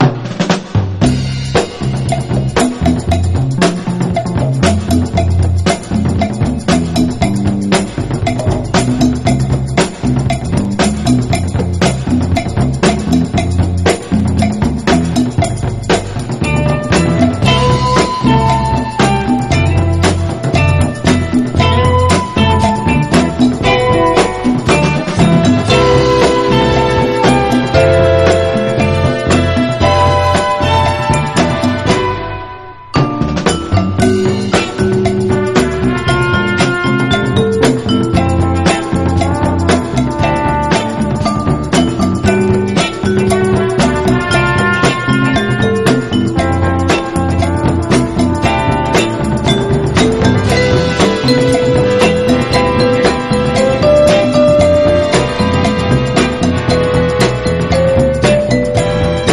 SOUL / SOUL / FREE SOUL / DRUM BREAK / NORTHERN SOUL
鬼ぶっといドラムが最高です！
カウベルの響きが効いてます！